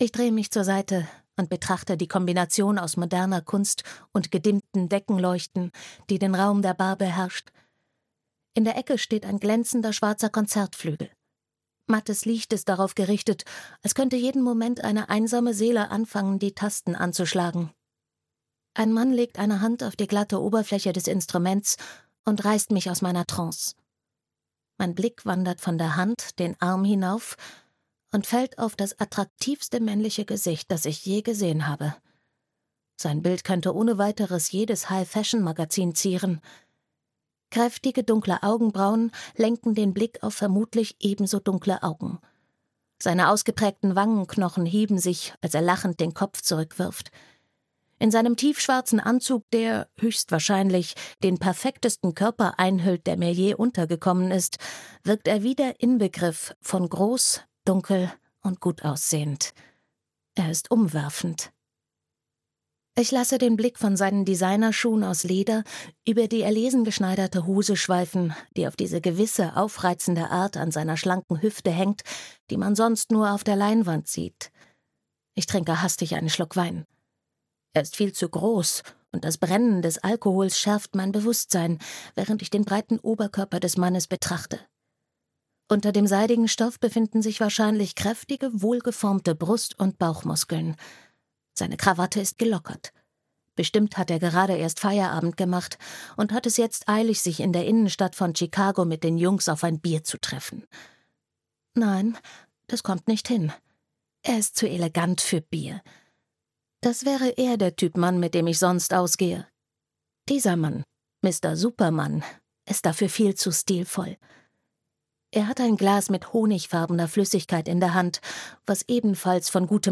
Trinity - Verzehrende Leidenschaft (Die Trinity-Serie 1) - Audrey Carlan - Hörbuch